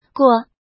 怎么读
guò
过 [guò]